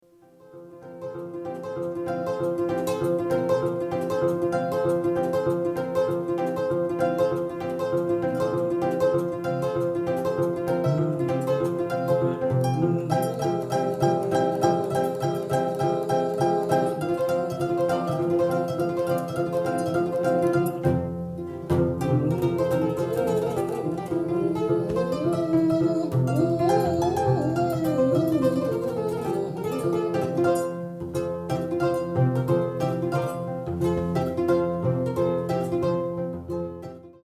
Kora